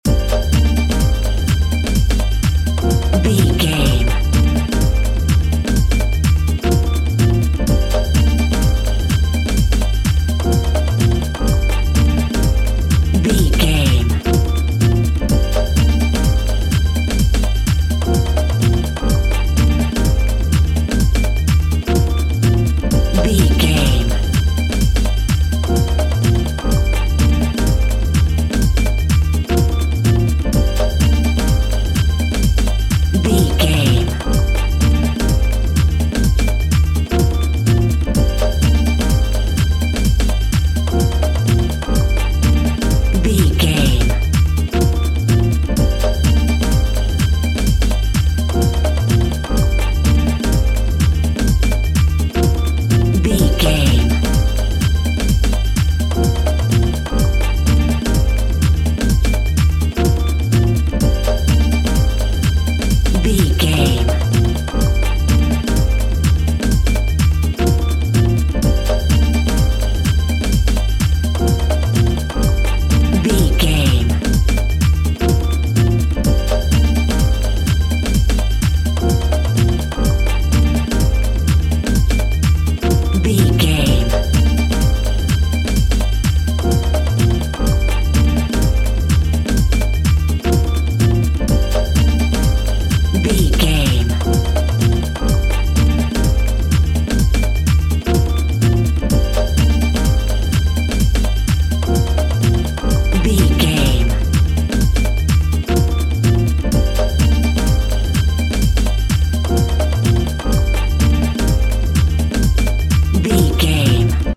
Aeolian/Minor
funky
groovy
uplifting
futuristic
driving
energetic
strings
bass guitar
synthesiser
electric piano
percussion
drums
house
techno
electro
synth pop
synth drums
synth leads
synth bass